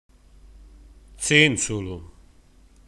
Alcuni vocaboli del dialetto Ruffanese.